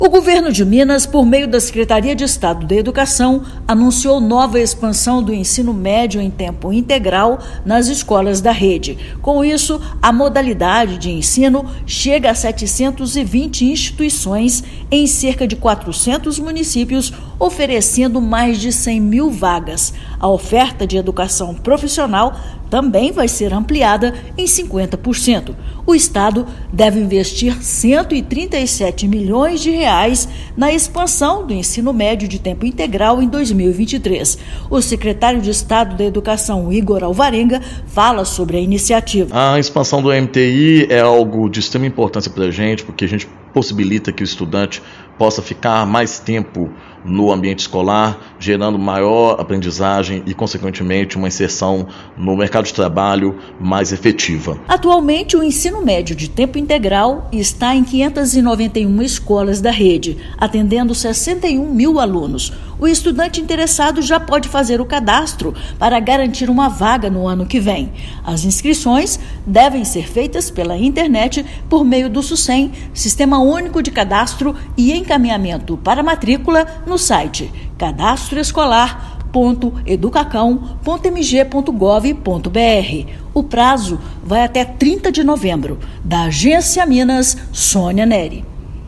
O Governo de Minas, por meio da Secretaria de Estado de Educação (SEE/MG), anuncia uma nova expansão do Ensino Médio em Tempo Integral (EMTI) nas escolas da rede estadual mineira. Ouça matéria de rádio.